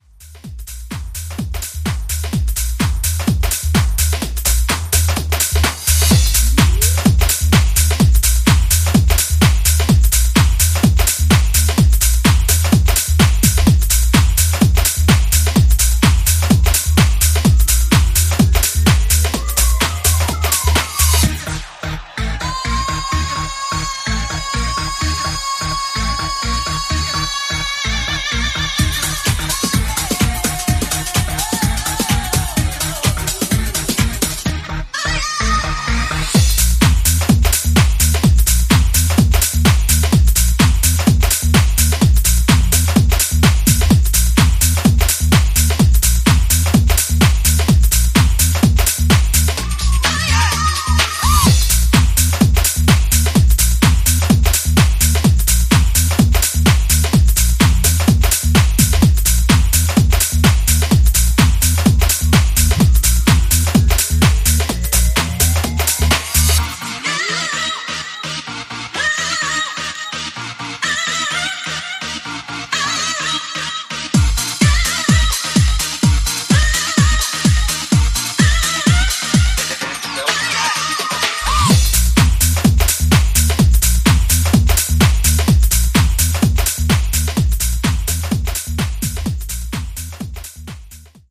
ジャンル(スタイル) HOUSE / DEEP TECH